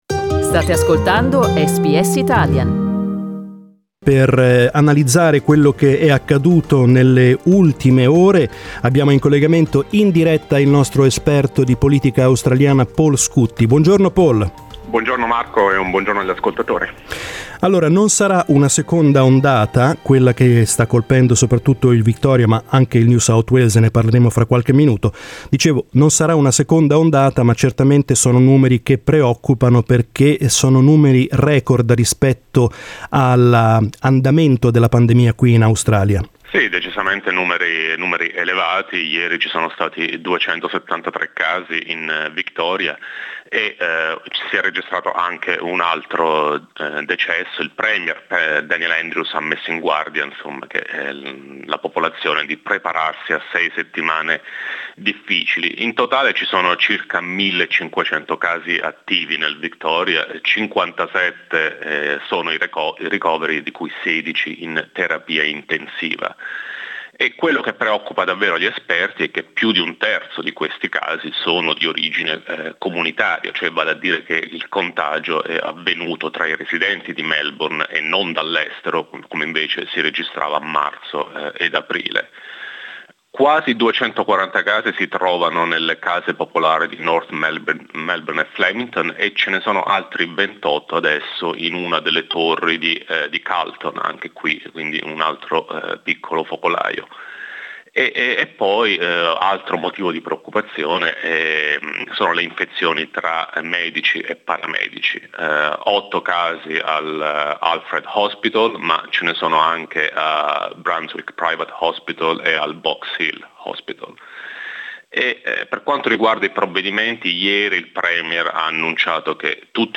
Della situazione nei due piu popolosi stati australiani abbiamo parlato con l'esperto di politica federale